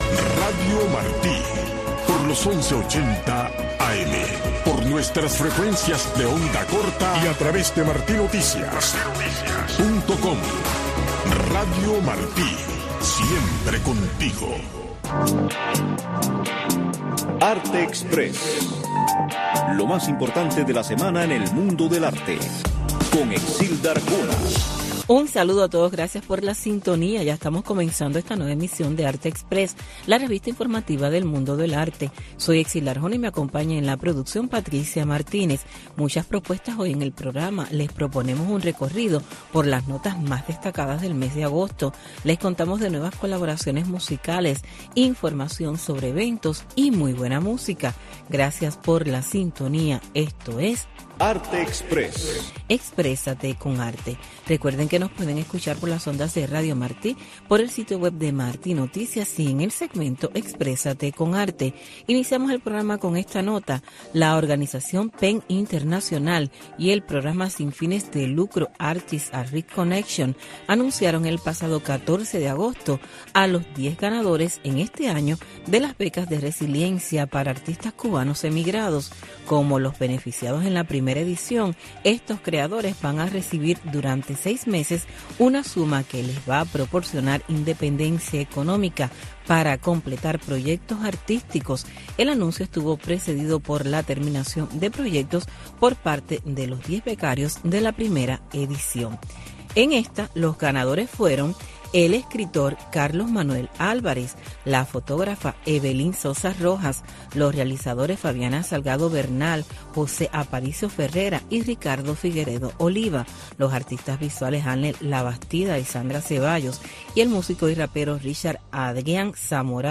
El músico cubano fusiona en el tema "La Rumba de Sandokan" el guaguancó con el sonido del sitar, un instrumento originario de la India.